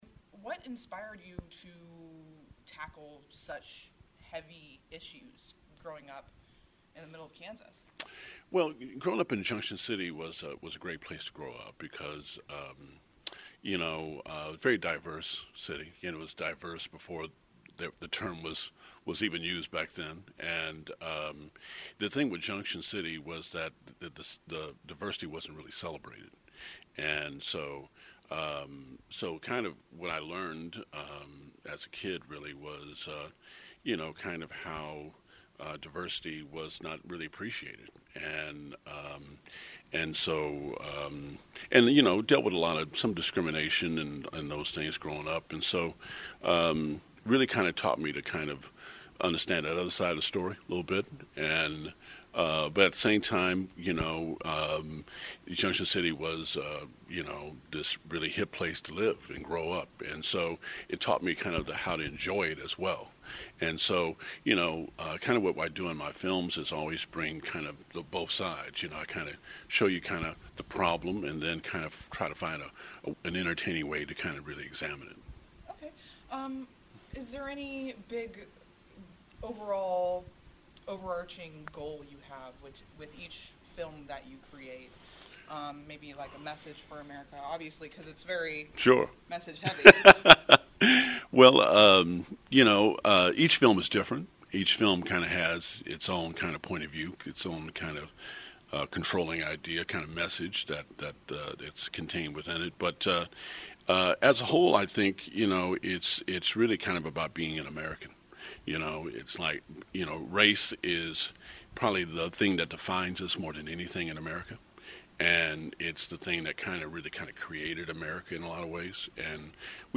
Willmott’s full interview